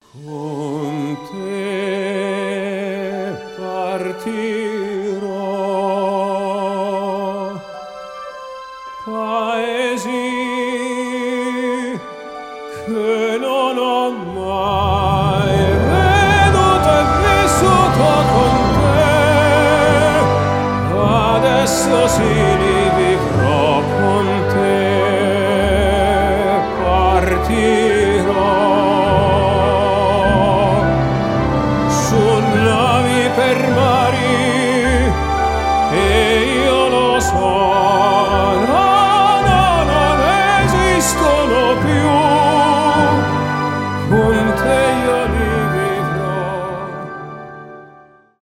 красивый вокал , романтические
поп , опера